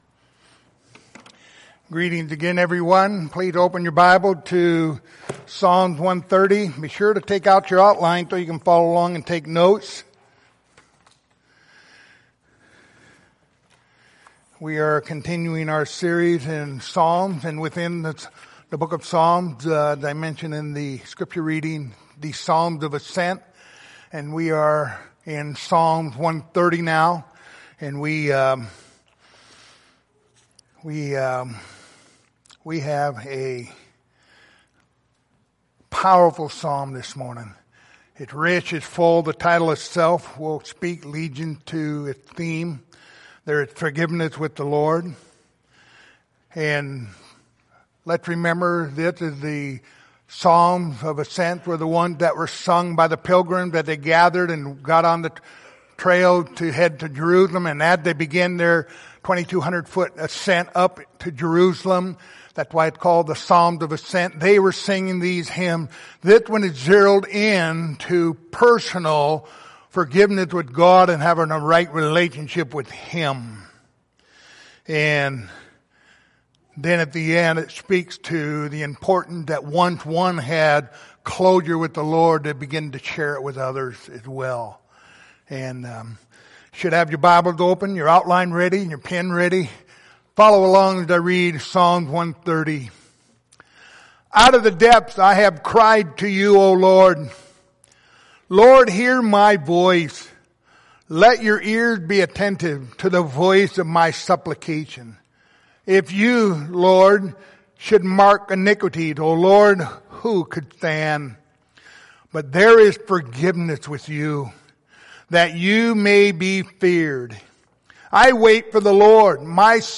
Passage: Psalm 130:1-8 Service Type: Sunday Morning Topics